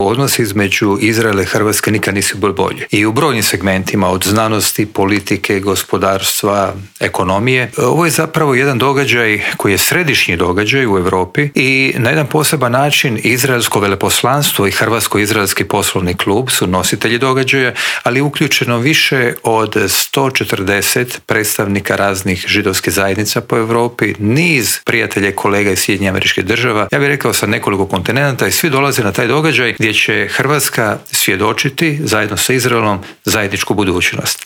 ZAGREB - Uoči 26. godišnjice diplomatskih odnosa između Hrvatske i Izraela, u Intervjuu tjedna Media servisa gostovao je profesor i znanstvenik Dragan Primorac koji nam je otkrio kako je došlo do uske veze između te dvije zemlje od gospodarstva, znanosti do sporta, a dotaknuli smo se i novi trendova i iskoraka u medicini.